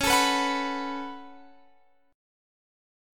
Listen to C#7sus4#5 strummed